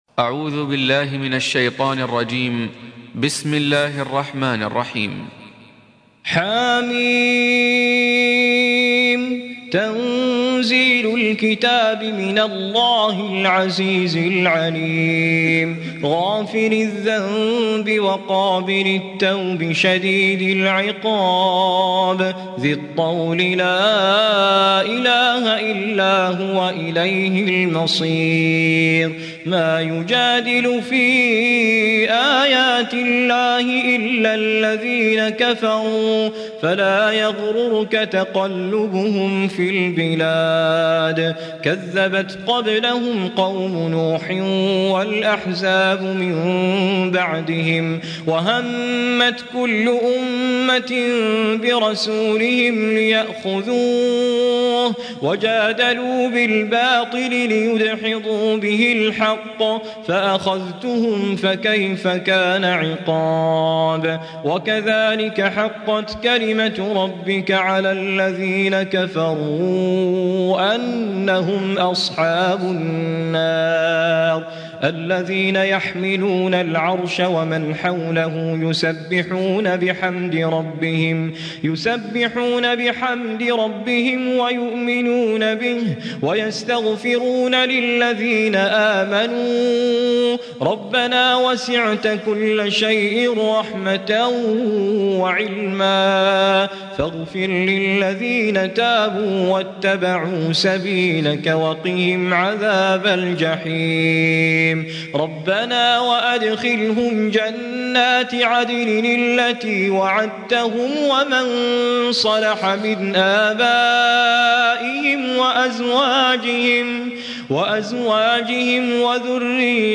40. Surah Gh�fir سورة غافر Audio Quran Tarteel Recitation
Surah Repeating تكرار السورة Download Surah حمّل السورة Reciting Murattalah Audio for 40. Surah Gh�fir سورة غافر N.B *Surah Includes Al-Basmalah Reciters Sequents تتابع التلاوات Reciters Repeats تكرار التلاوات